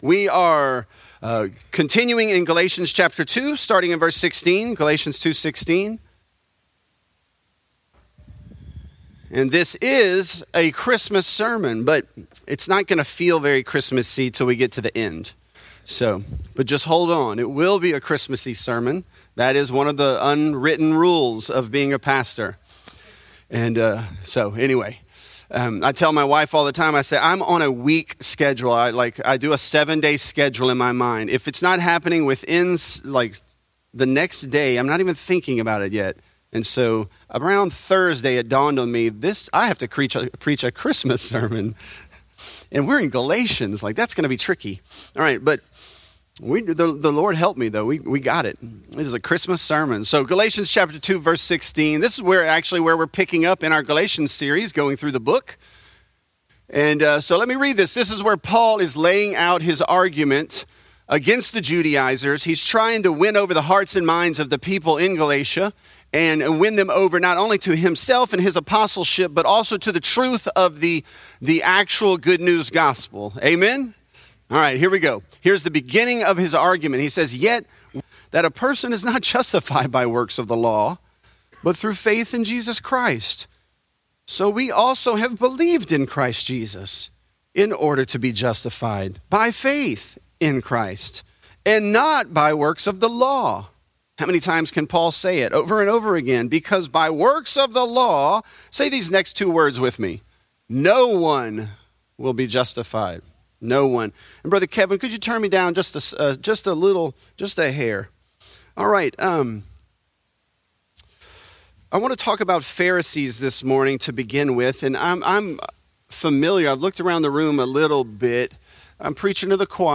Galatians: The Law As A Ladder | Lafayette - Sermon (Galatians 1)